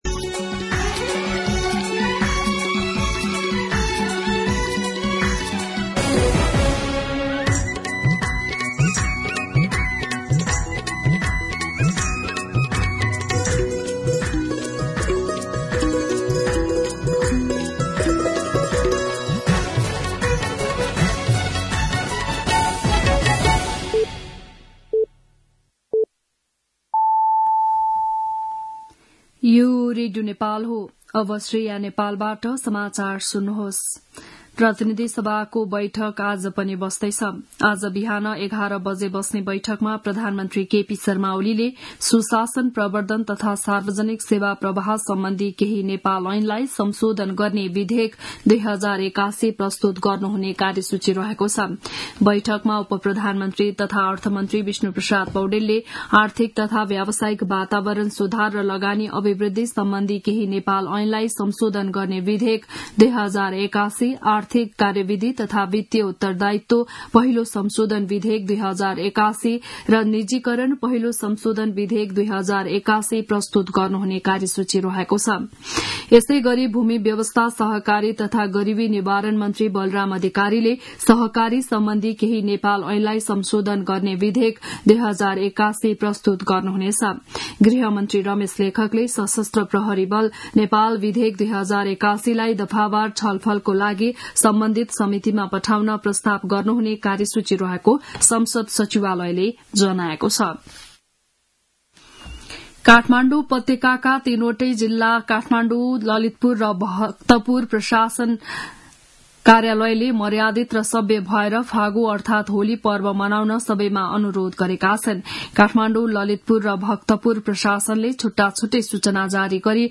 An online outlet of Nepal's national radio broadcaster
बिहान ११ बजेको नेपाली समाचार : २९ फागुन , २०८१